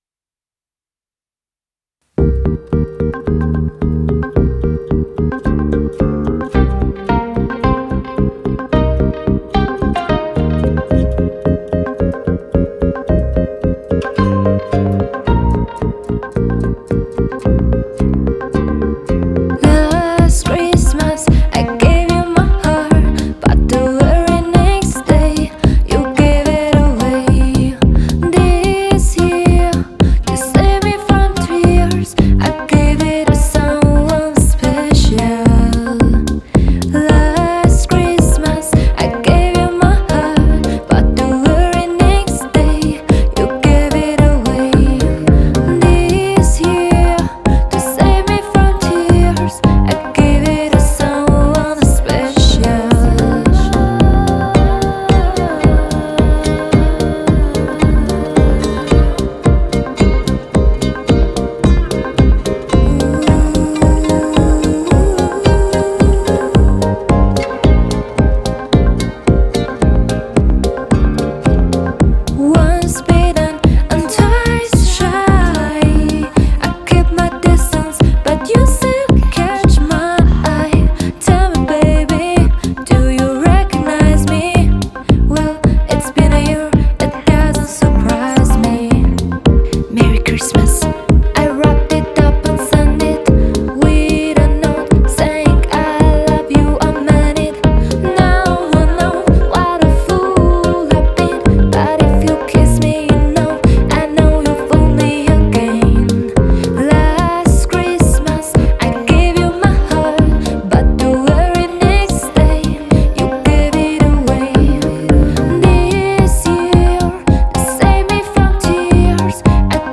• cover